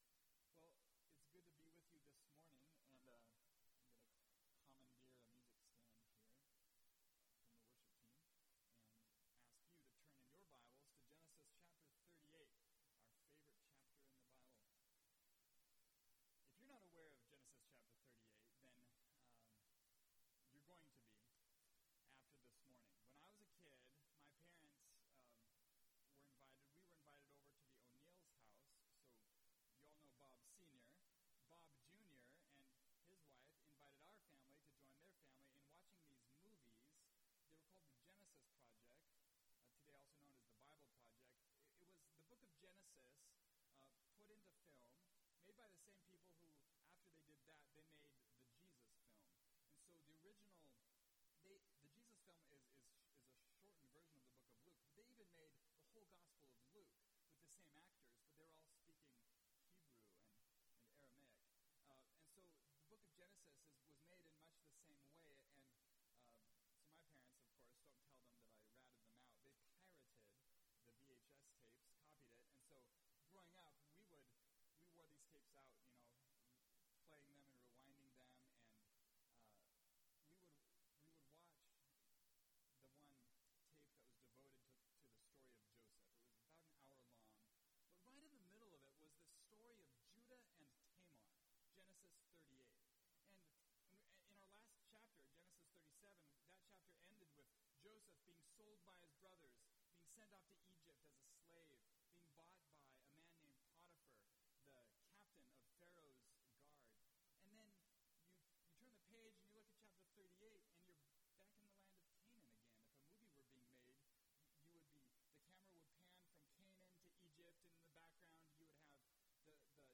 It’s About Jesus (Genesis 38:1-30) – Mountain View Baptist Church